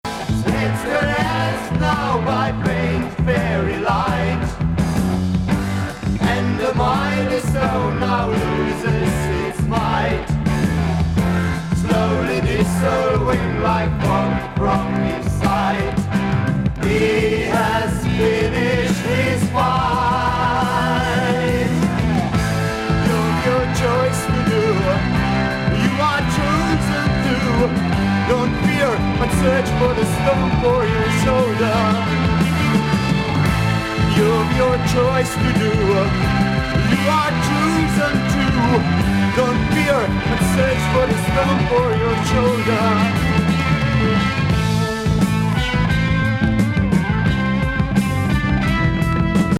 HARD ROCK GROOVE B3″WITH